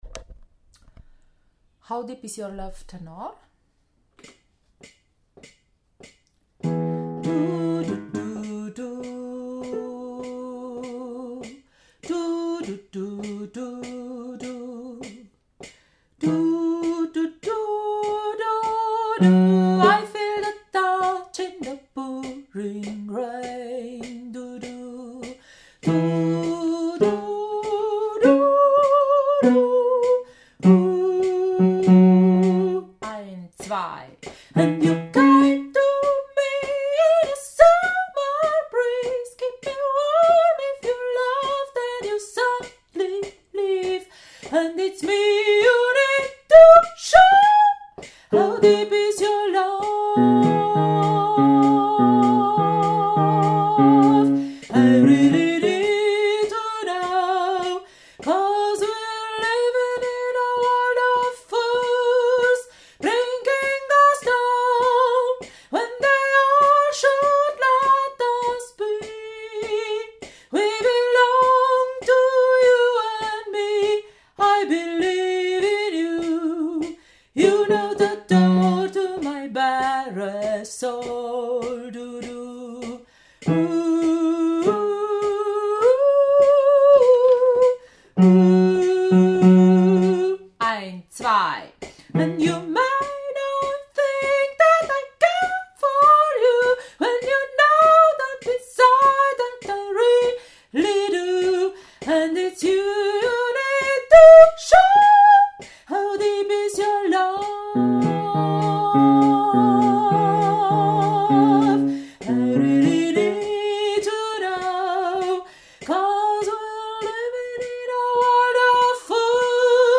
How deep is your love – Tenor
How-deep-is-your-love-Tenor.mp3